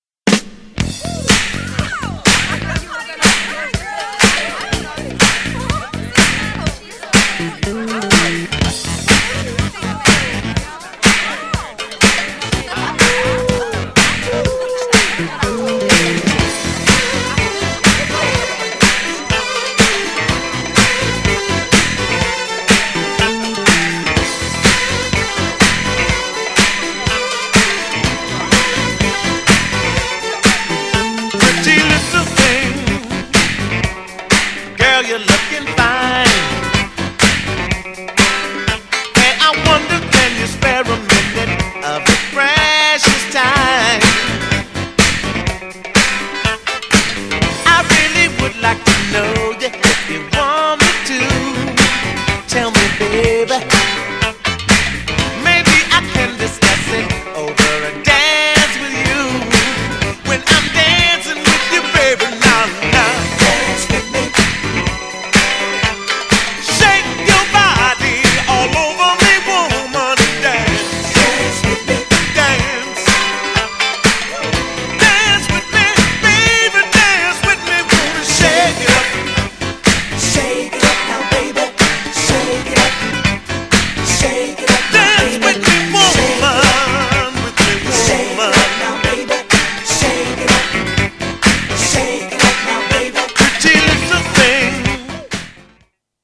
GENRE Dance Classic
BPM 116〜120BPM
エレクトロ # シンセ # ソウル